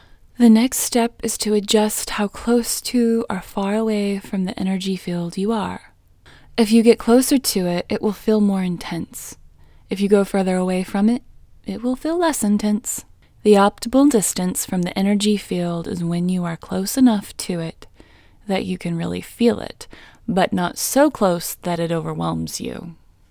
IN Technique First Way – Female English 6